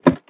ladder2.ogg